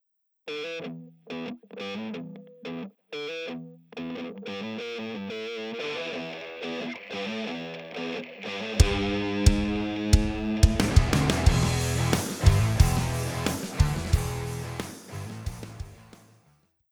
If you listen closely, with a very over-driven hi-gain amp (read the provided TXT file on the setup), maybe even an additional fuzz distortion pedal.
This is a quick edit of the song, created on 2" speakers.
As hi-gain amp I opted for a "Bogner Überschall" (HELIX Native sadly doesn't have a Peavy).
Again, this is but a demo with barely any additional EQ, compression or a specific console/tape sound (I find the drums a bit too harsh for example, a suitable tape effect might "tame" that), no good gain staging (faders are al over the place) and "mixed" on small speakers.